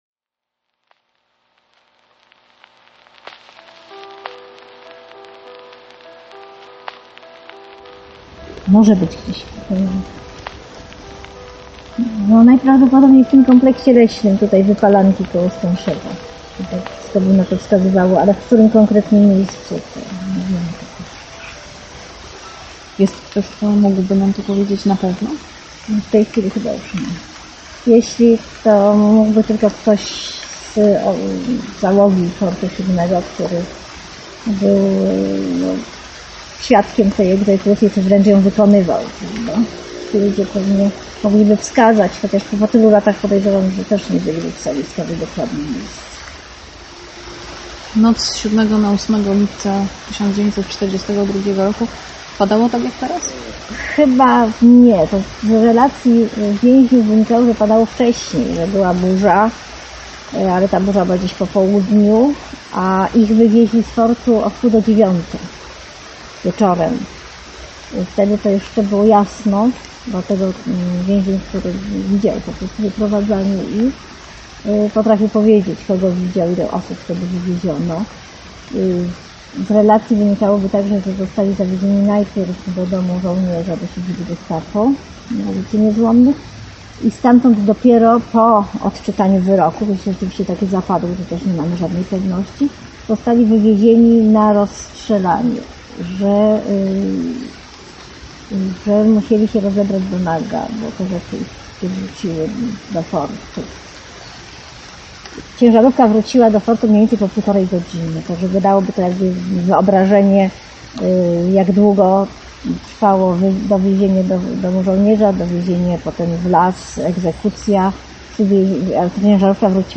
Po prostu zniknęli - reportaż